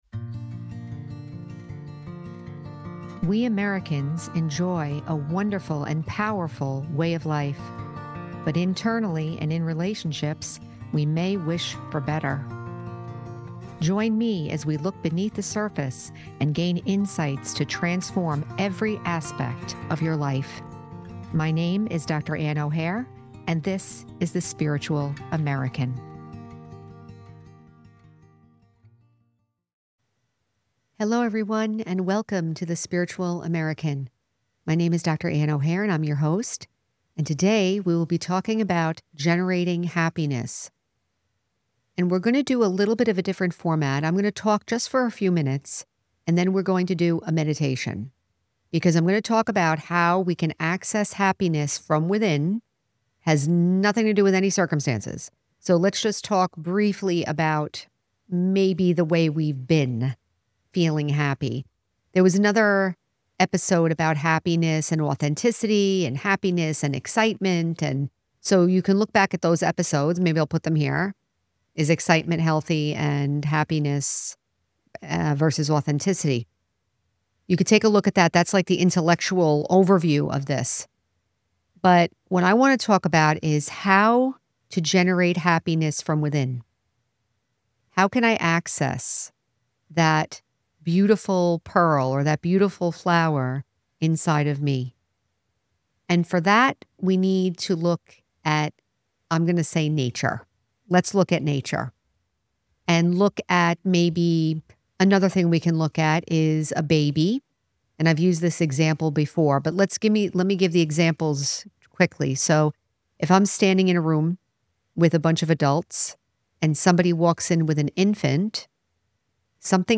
Includes guided meditation.